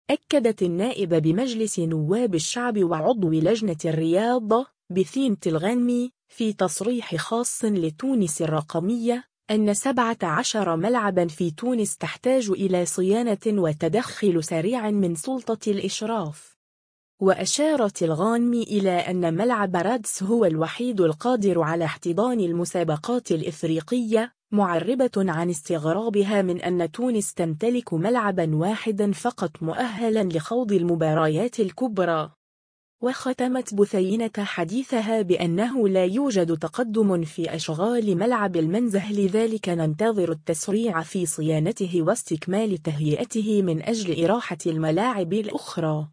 أكدت النائب بمجلس نواب الشعب و عضو لجنة الرياضة، بثينة الغانمي ، في تصريح خاص لـ”تونس الرقمية”، أن 17 ملعباً في تونس تحتاج إلى صيانة و تدخل سريع من سلطة الإشراف.